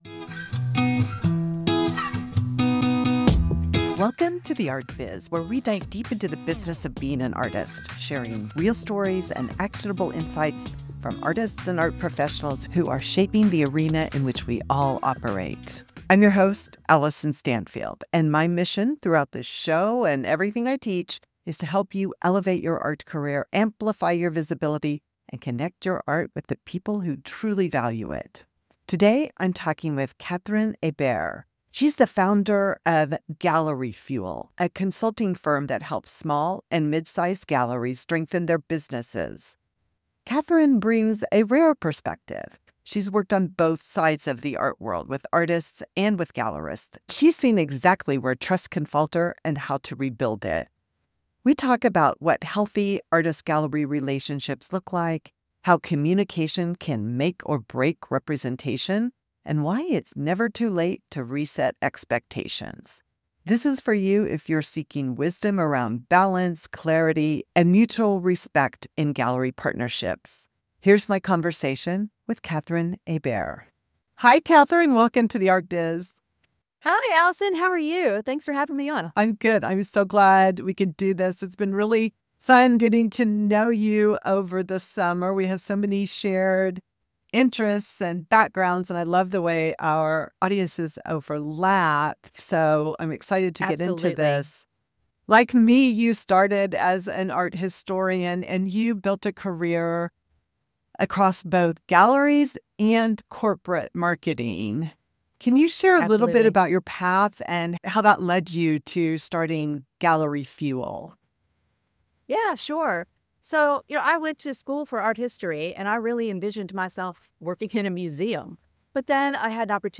Join the discussion on artist-gallery relationships as we explore how to foster trust and communication for thriving partnerships.